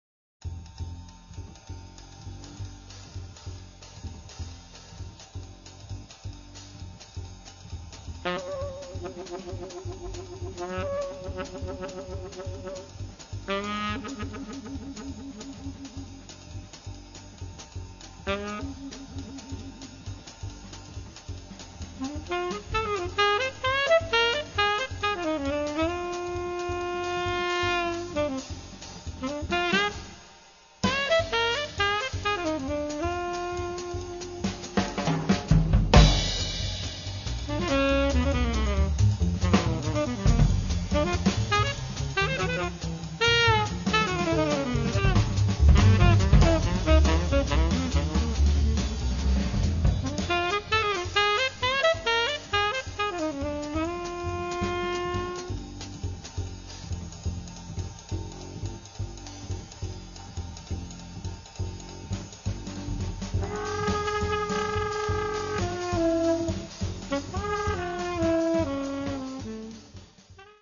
tenor saxophone
bass
drums